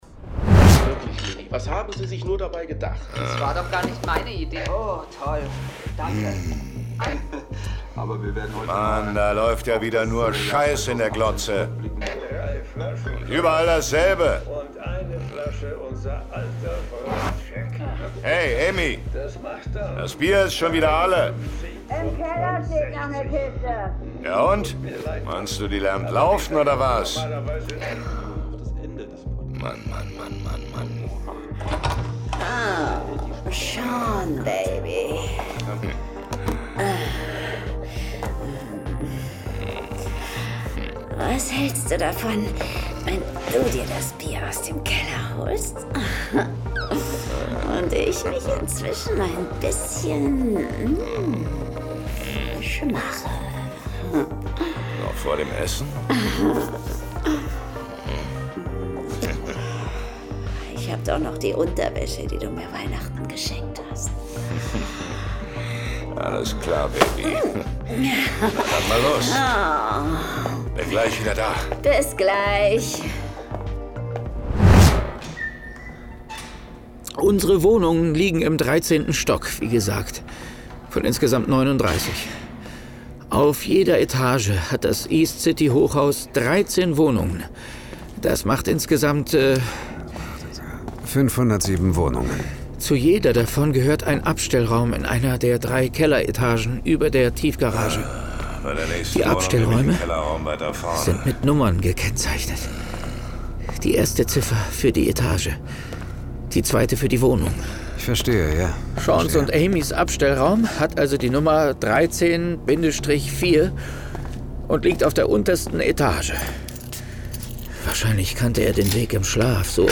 John Sinclair Classics - Folge 42 Das Hochhaus der Dämonen. Hörspiel.